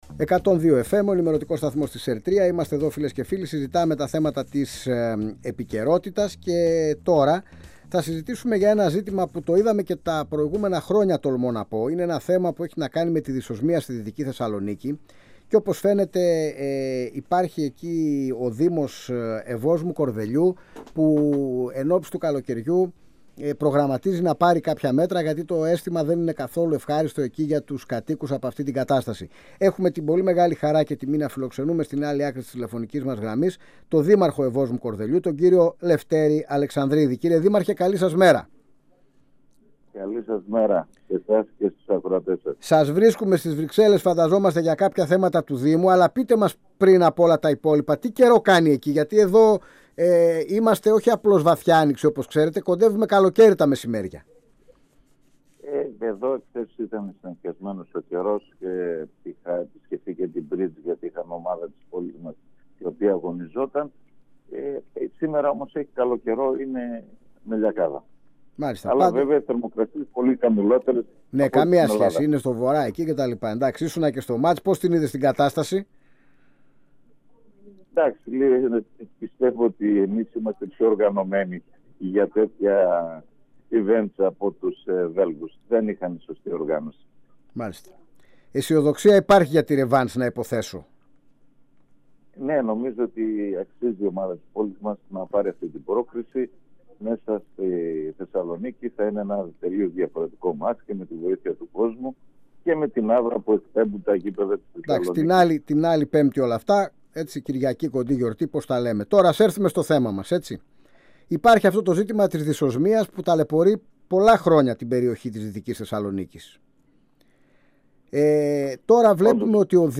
Στο πρόβλημα της έντονης δυσοσμίας, που καθιστά ανυπόφορη την καθημερινότητα των κατοίκων του Κορδελιού της Δυτικής Θεσσαλονίκης αναφέρθηκε ο Δήμαρχος Ευόσμου-Κορδελιού Λευτέρης Αλεξανδρίδης μιλώντας στην εκπομπή «Αίθουσα Σύνταξης» στον 102FM της ΕΡΤ3.